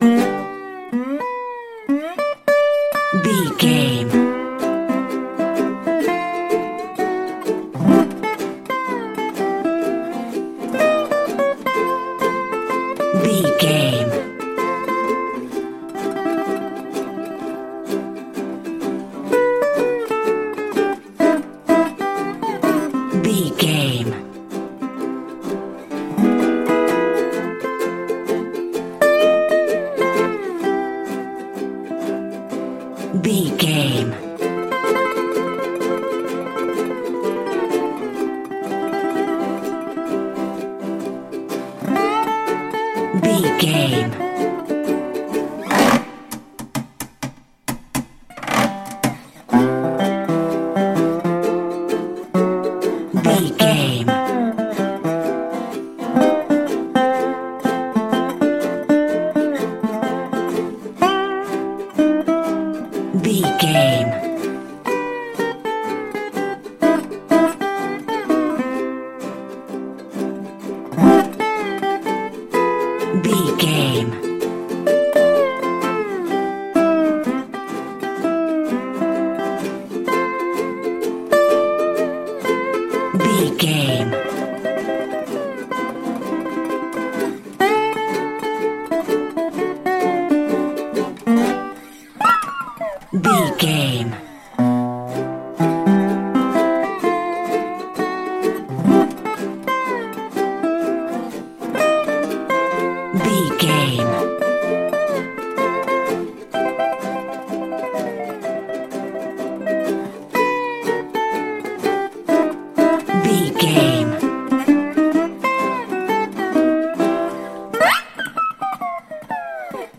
Ionian/Major
acoustic guitar
percussion
ukulele
dobro
slack key guitar